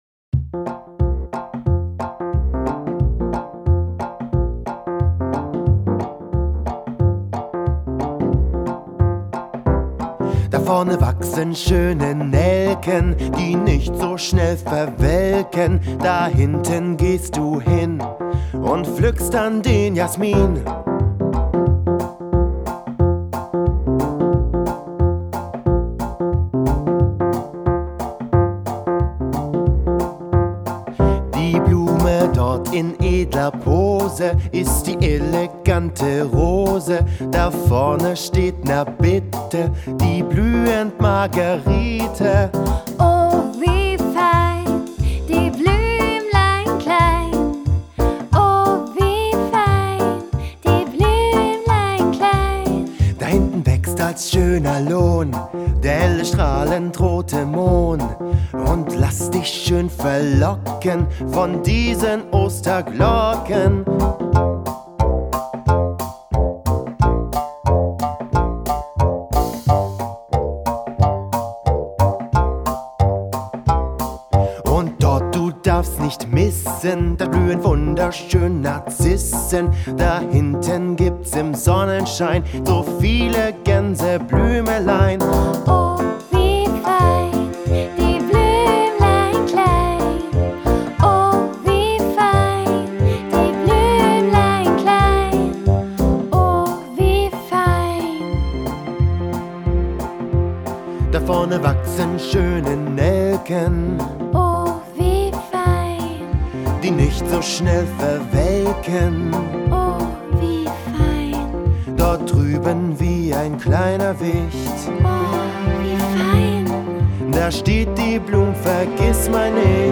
Kinderlieder